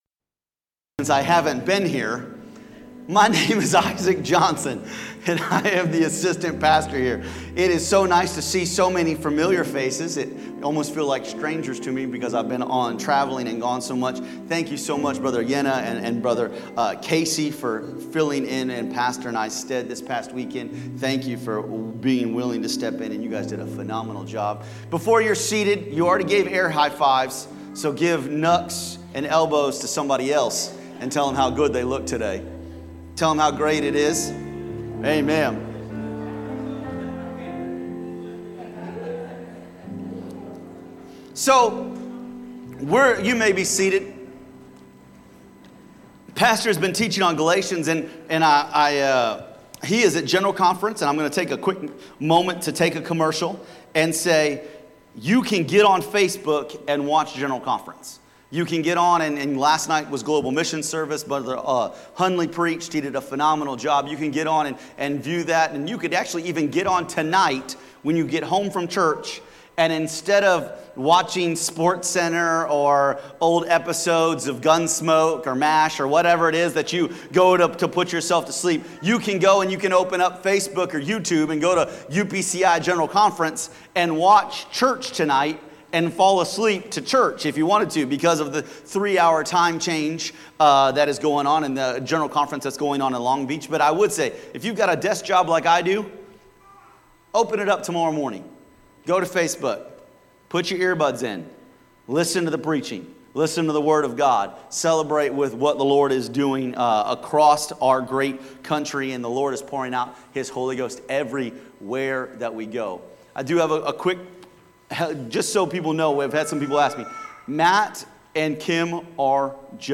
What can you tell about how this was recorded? Wednesday Service Encouraged to Encourage